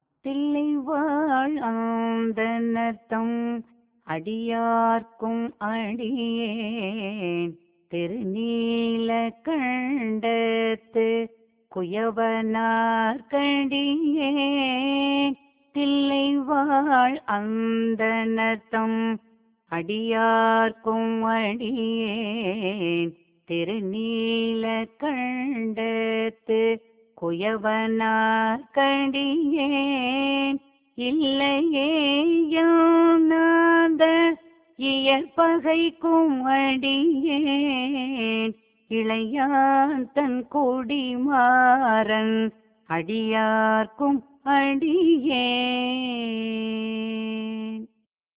சிவனடியார் பெயர் ஒவ்வொன்றையும் சொல்லி "இவர்க்கு நான் அடியேன்" என்று பாடினார். கொல்லிக் கௌவாணப் பண்ணில் திருத்தொண்டத் தொகை பாடினார்.
பண்: கொல்லிக் கௌவாணம்